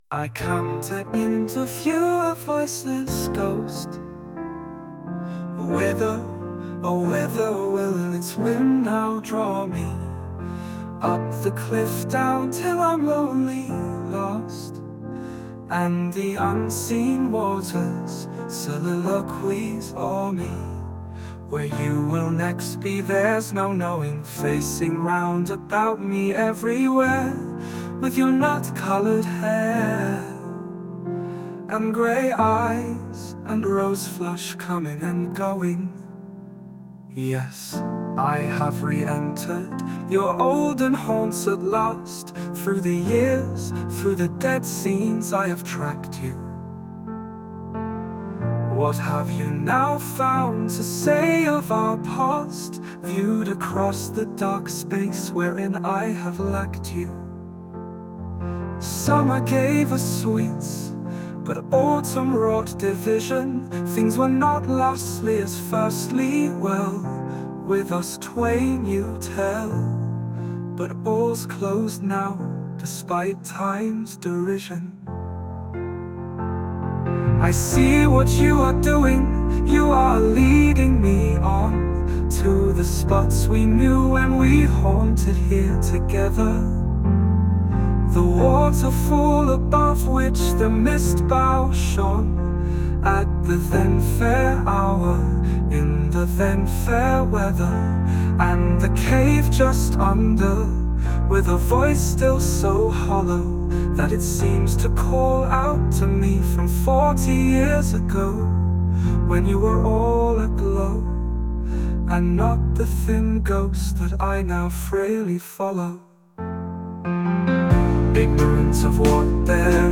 SUMO AI による作曲・演奏　MP3 このサイト上で